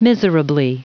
Prononciation du mot miserably en anglais (fichier audio)
Prononciation du mot : miserably